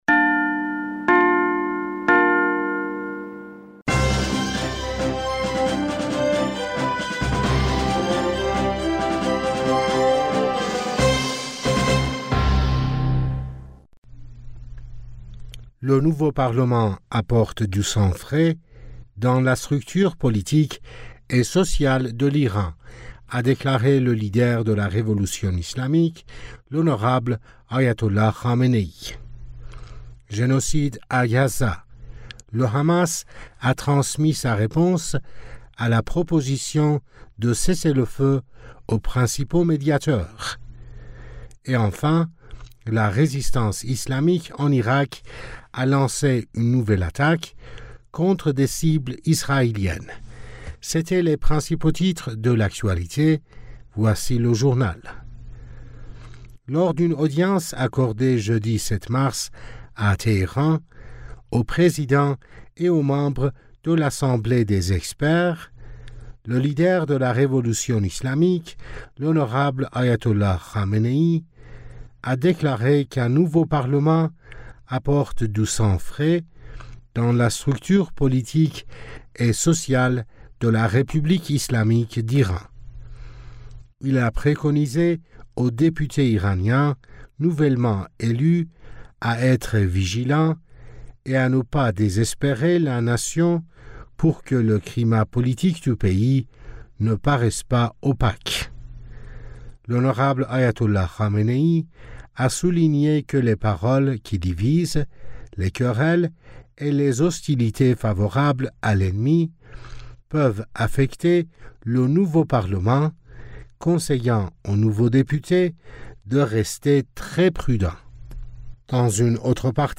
Bulletin d'information du 07 Mars 2024